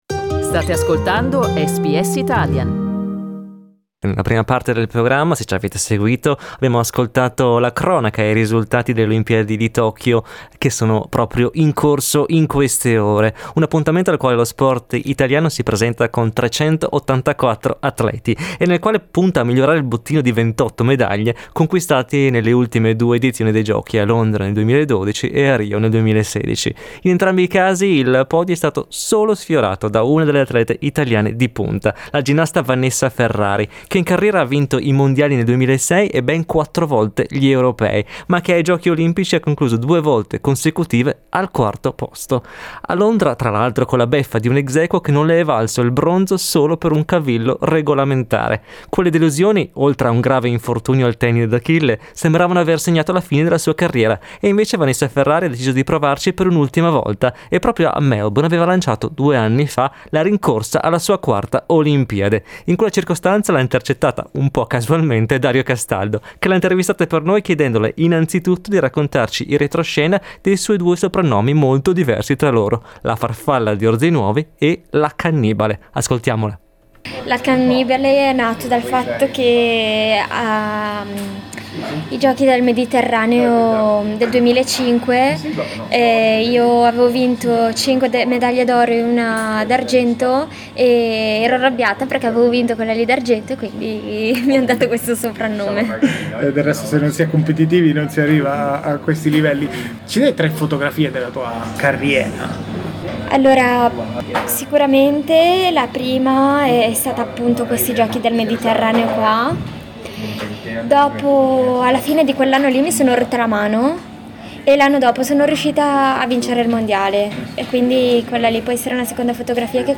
Riascolta l'intervista a Vanessa Ferrari: Le persone in Australia devono stare ad almeno 1,5 metri di distanza dagli altri.